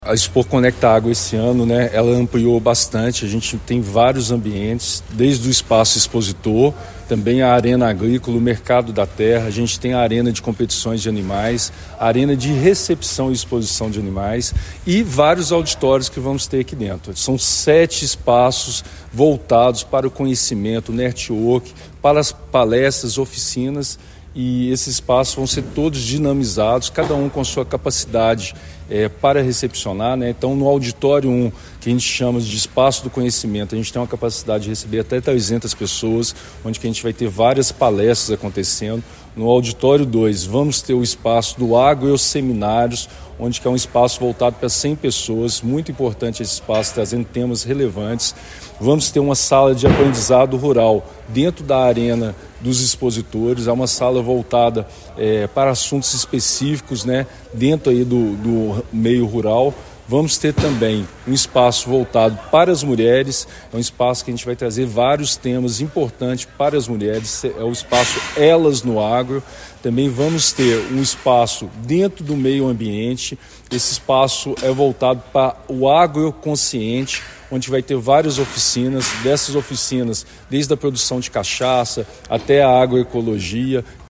Além disso mais de 80 expositores participaram da feira, índice que será superado, como destaca o secretario municipal de Esporte, Lazer e Turismo, Helton Simão: